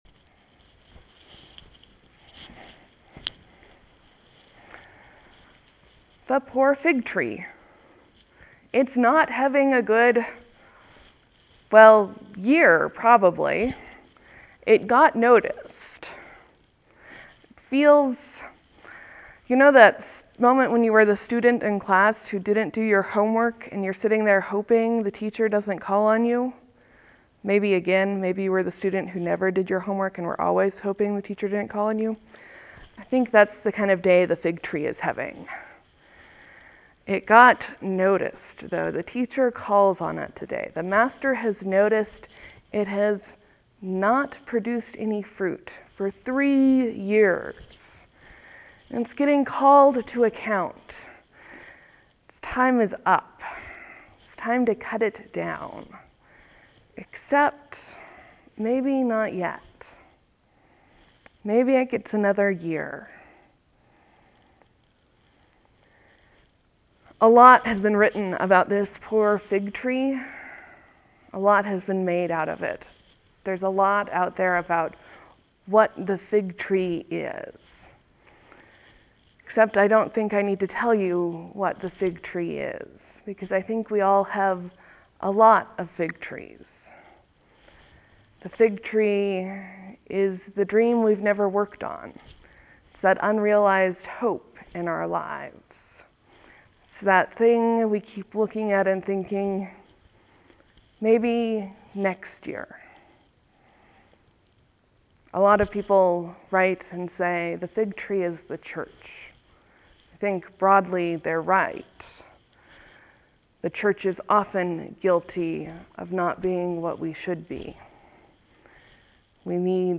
The Fig Tree and the Gardener, a sermon for Lent 3 3-3-13
(There will be a few moments of silence before the sermon begins.